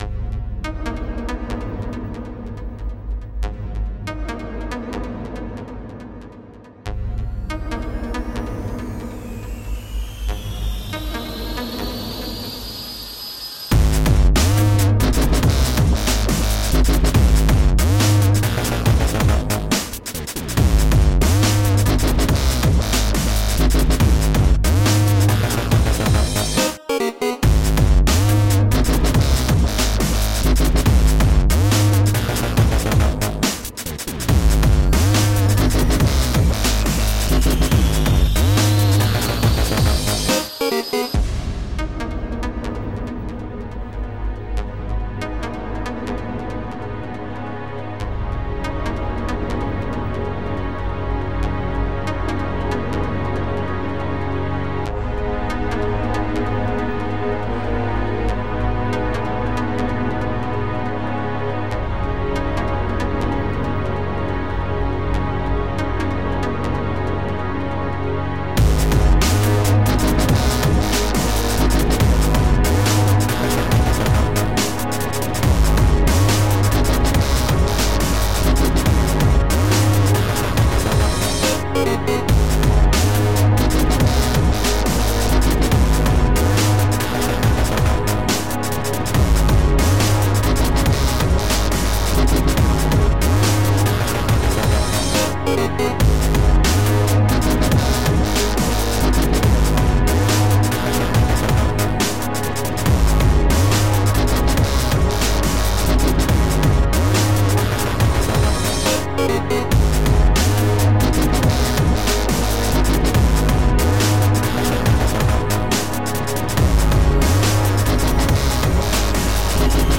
Melodic dubstep and heavy electronic stimulation.
Each track has been carefully crafted via emotion and mood.
Tagged as: Electronica, Industrial